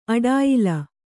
♪ aḍāyila